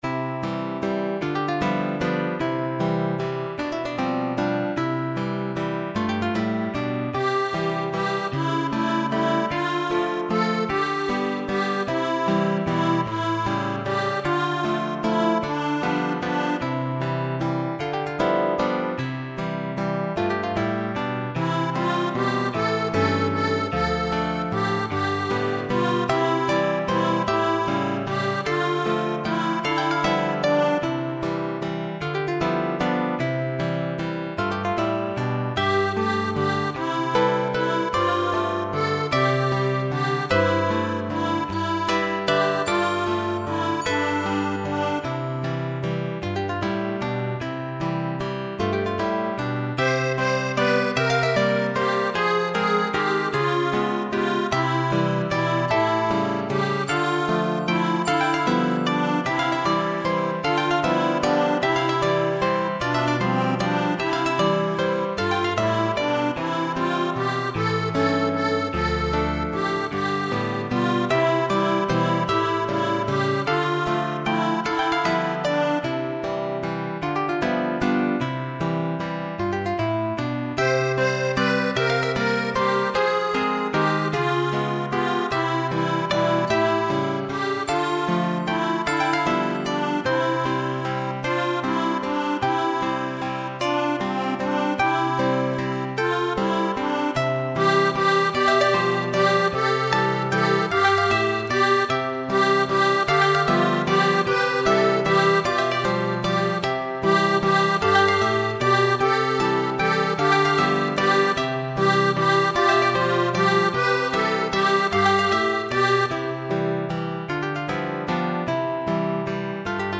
Composizioni per coro di voci bianche:
Scarica la base - mp3 2 Mb